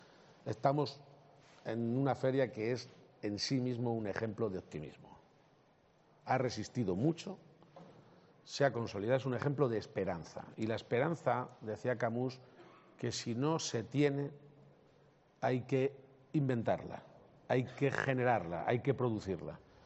>> Así lo ha anunciado el presidente de Castilla-La Mancha en la inauguración de la XLI edición de la Feria Regional de Artesanía